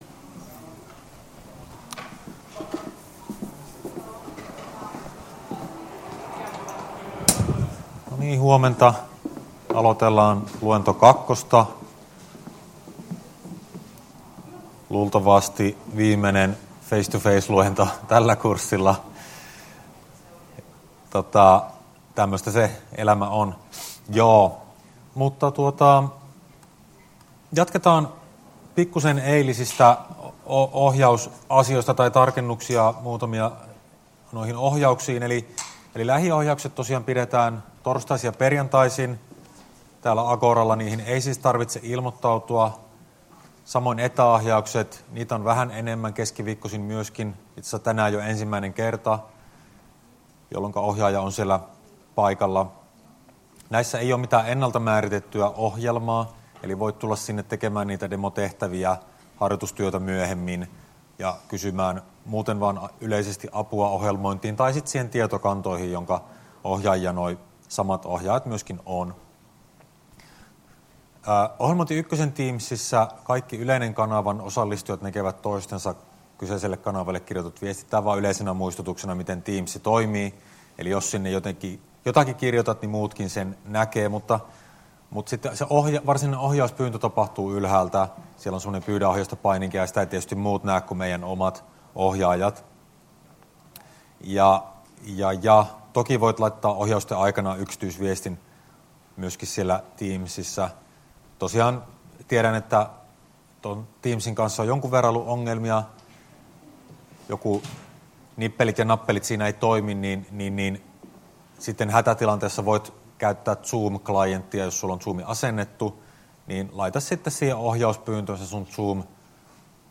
Luento 2 032f1a1e0d23478ababe62d33247fc7a